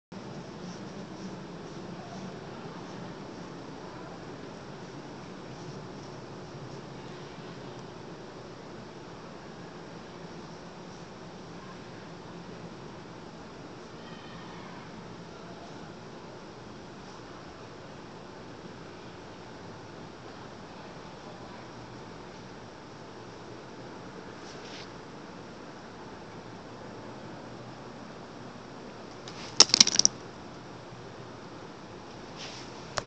Field Recording Numer Siete
Location: 4/9/2014, 11:40A, Before Emily Lowe Hall.
Sounds Featured: Item shattering next to me